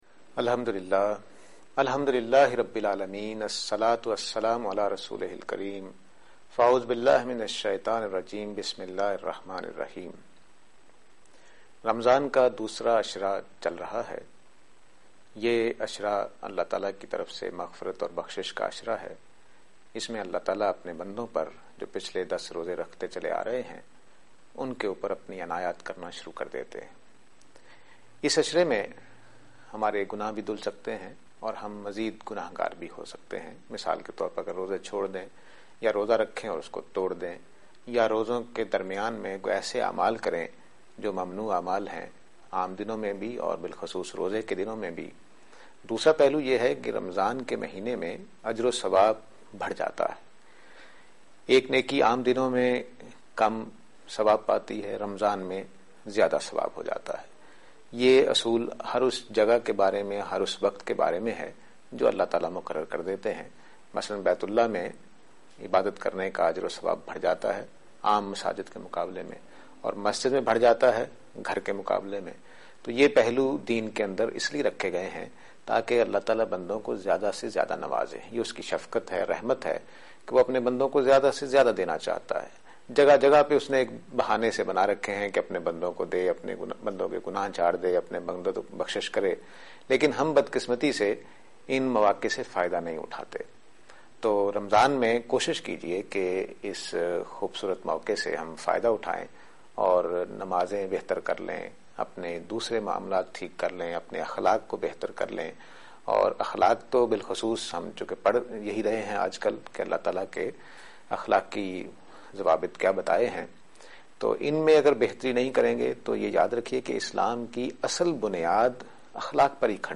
Program Tazkiya-e-Ikhlaq on Aaj Tv.